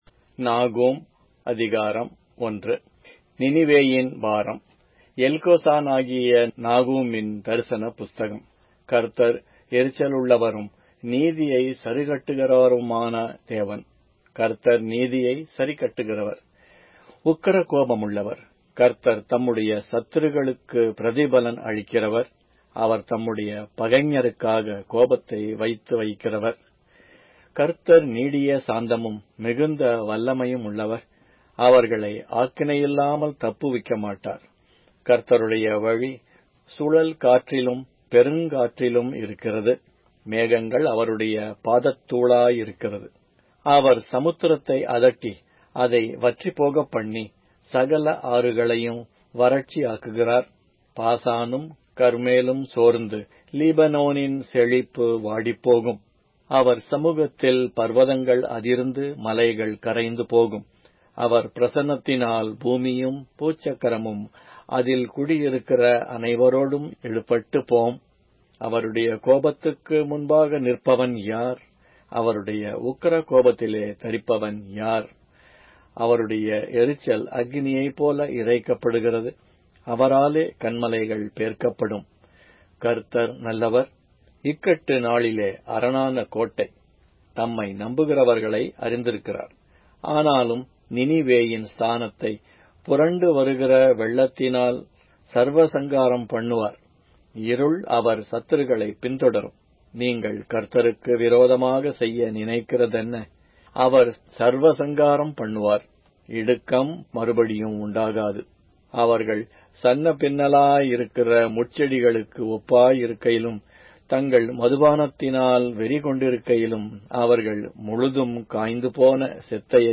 Tamil Audio Bible - Nahum 2 in Tov bible version